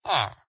sounds / mob / villager / idle3.ogg